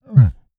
MONSTER_Ugh_01_mono.wav